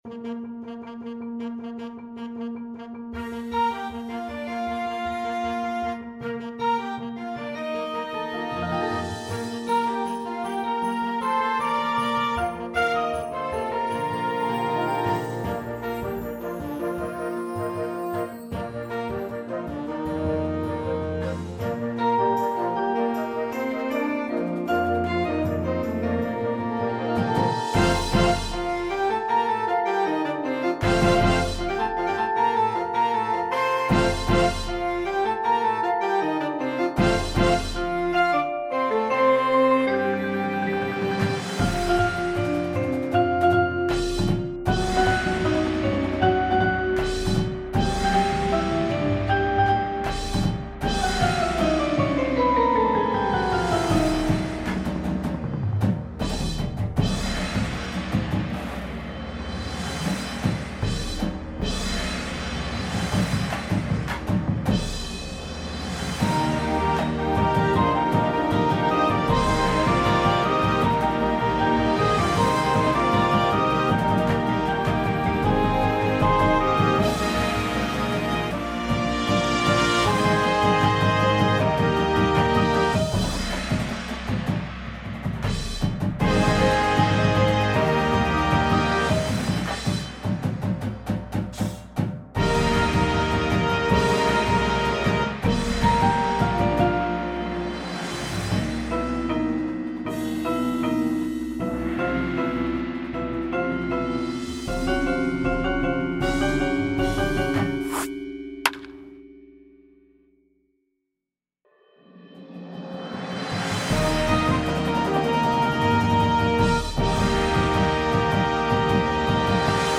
• Flute
• Trombone 1, 2
• Snare Drum